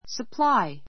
supply səplái サ プ ら イ